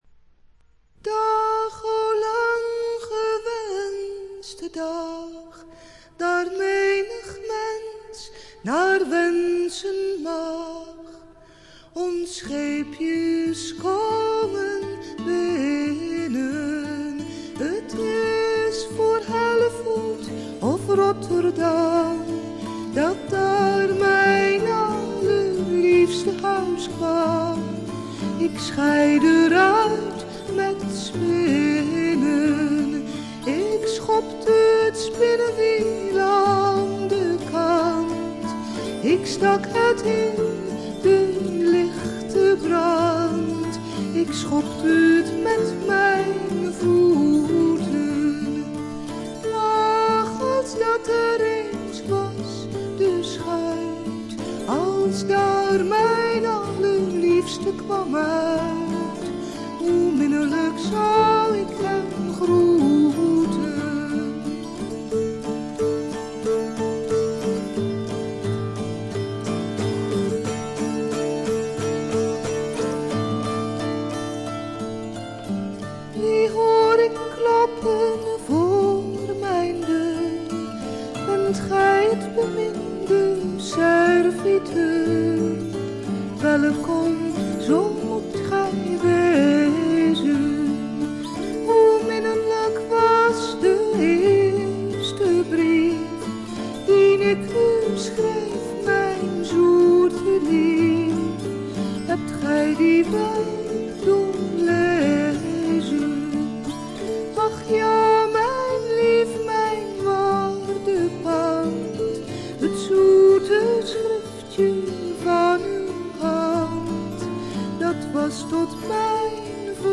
オランダのトラッド・グループ
アコースティック楽器のみのアンサンブルで美しいトラディショナル・フォークを聴かせます。
試聴曲は現品からの取り込み音源です。